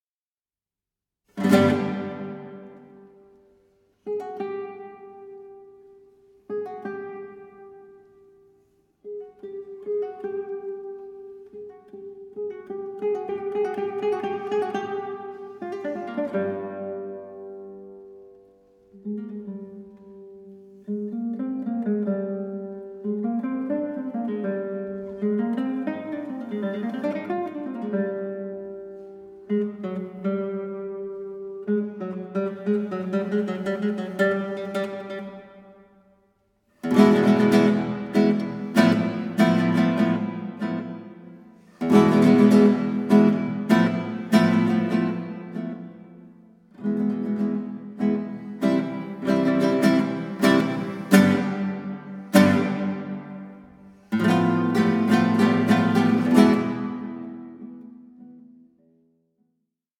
INTROSPECTIVE EXPLORATION OF FRENCH-SPANISH MUSICAL BORDERS